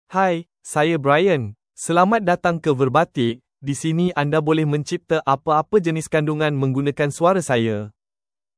Brian — Male Malay (Malaysia) AI Voice | TTS, Voice Cloning & Video | Verbatik AI
Brian is a male AI voice for Malay (Malaysia).
Voice sample
Listen to Brian's male Malay voice.
Brian delivers clear pronunciation with authentic Malaysia Malay intonation, making your content sound professionally produced.